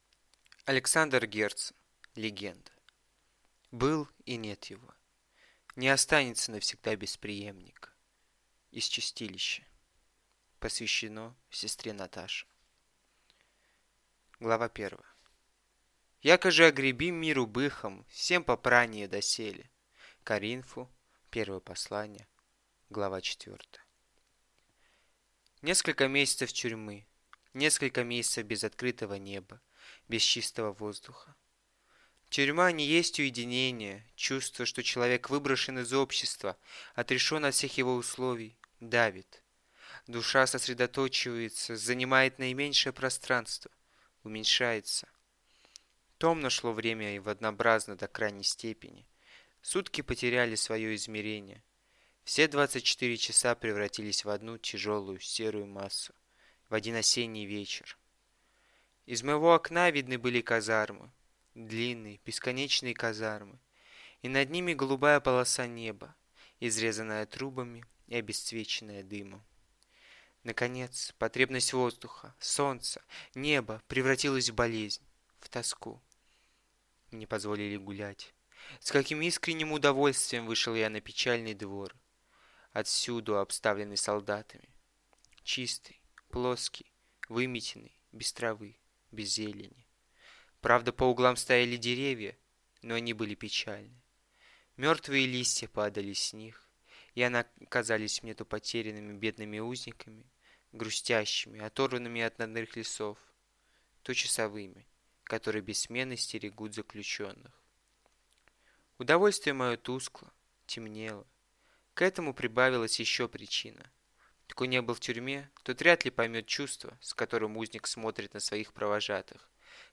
Аудиокнига Легенда | Библиотека аудиокниг